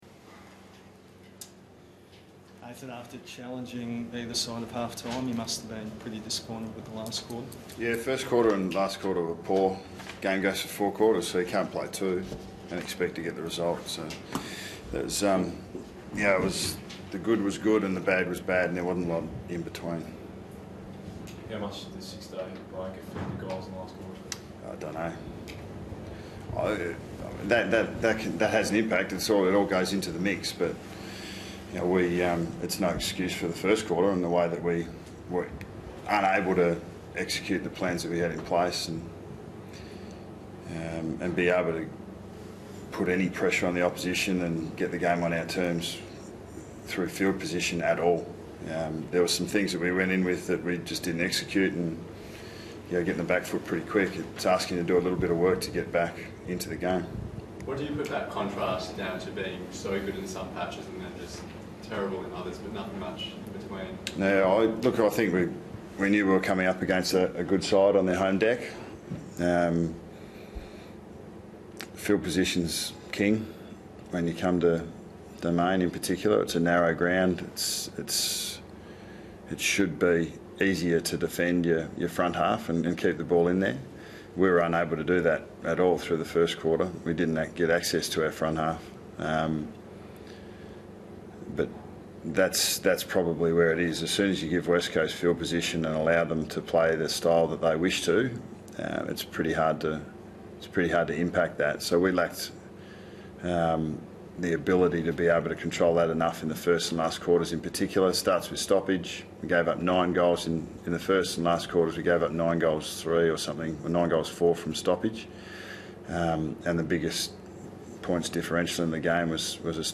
Listen to coach Nathan Buckley take questions from the media following Collingwood's round six loss to West Coast on 1 May 2016.